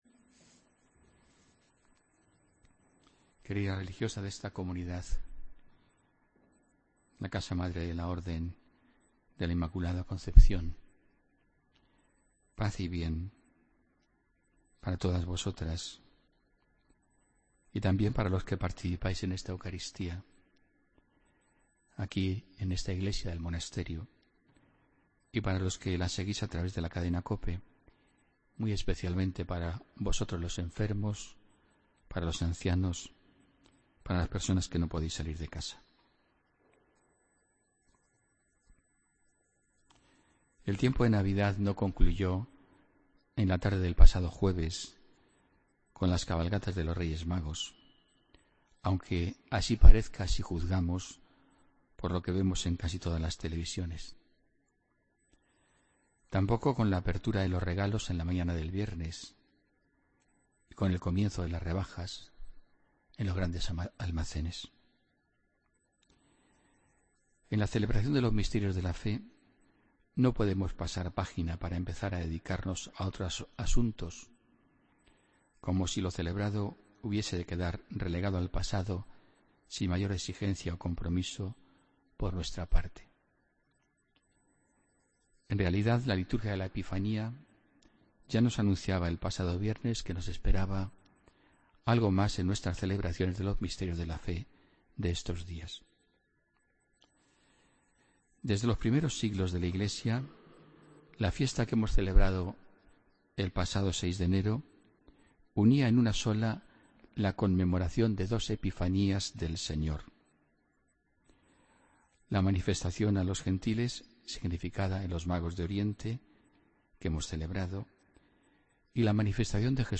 AUDIO: Homilía del domingo 8 de enero de 2017